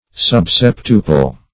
Search Result for " subseptuple" : The Collaborative International Dictionary of English v.0.48: Subseptuple \Sub*sep"tu*ple\, a. Having the ratio of one to seven.